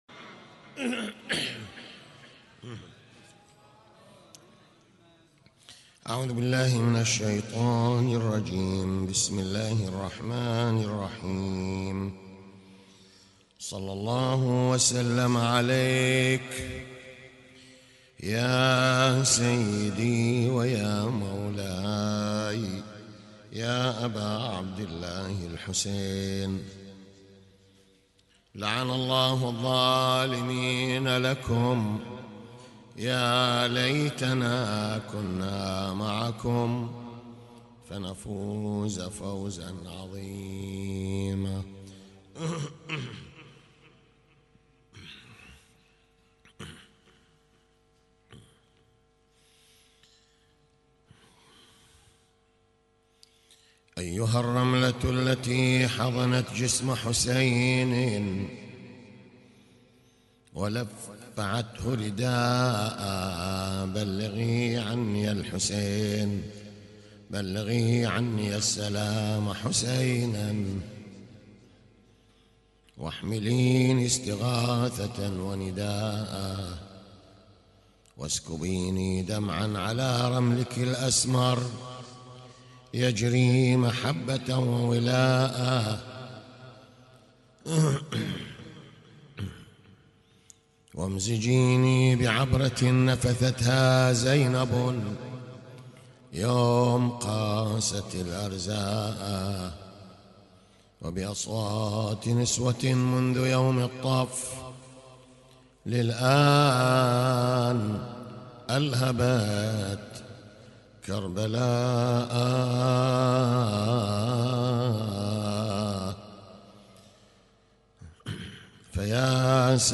تغطية صوتية: يوم سادس محرم 1438هـ في المأتم
يوم 6 محرم 1438هـ - مجلس العزاء